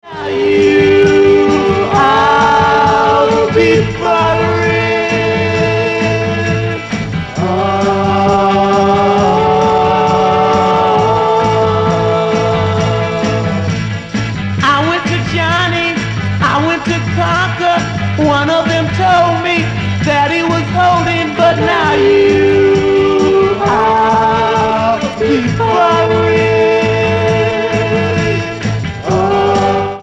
Pop, Rock, Psyche, Folk